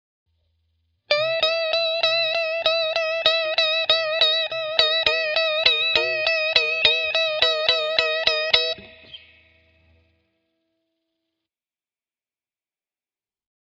How To Make A Crying Guitar Sound
Listen to this bend vibrato technique
To add even more drama to this bend vibrato, hit the high e-string with several upstrokes while you’re performing the bend vibrato.